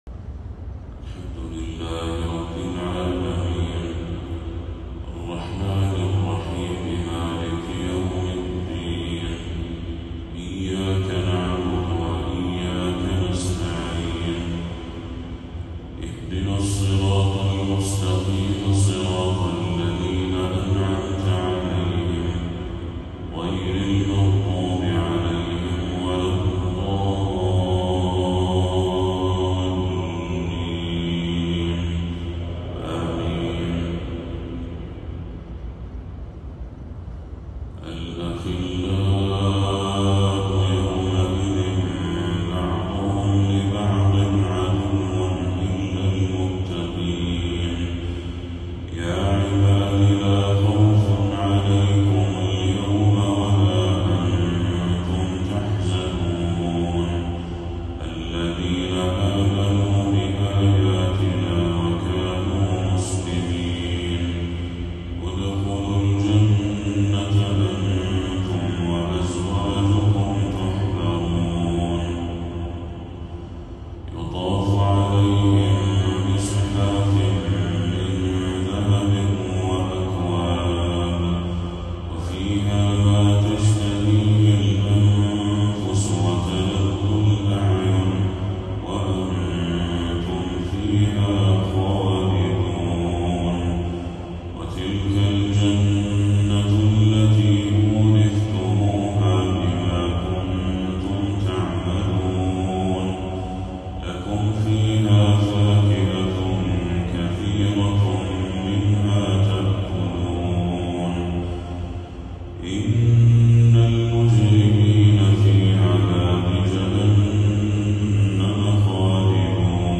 تلاوة آسرة لخواتيم سورة الزخرف للشيخ بدر التركي | فجر 25 صفر 1446هـ > 1446هـ > تلاوات الشيخ بدر التركي > المزيد - تلاوات الحرمين